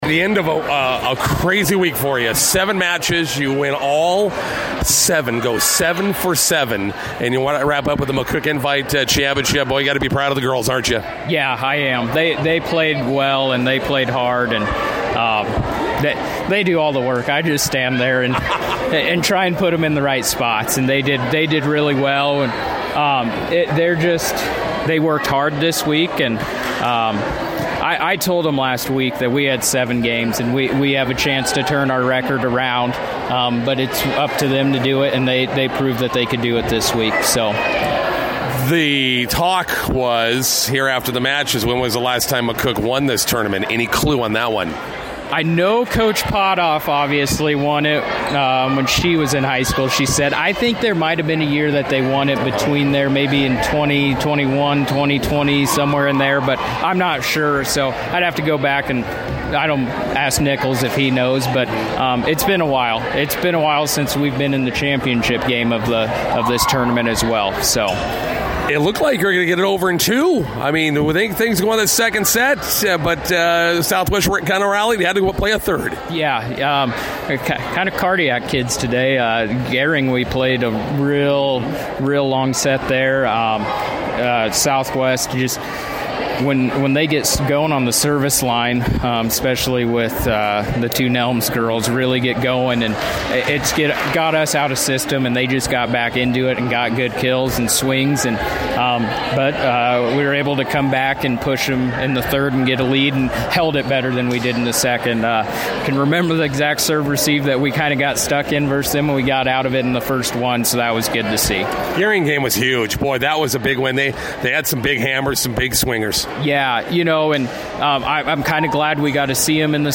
INTERVIEW: Bison volleyball wraps up a busy week with a tournament invite title.